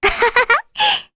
Rehartna-Vox_Happy2_kr.wav